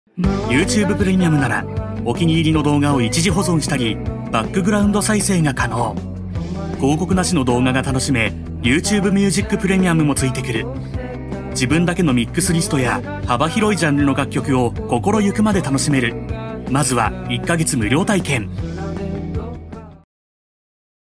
AUDIO DEMOS
1. Commercial Calm 0:21